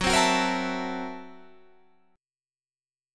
Gb7#9b5 chord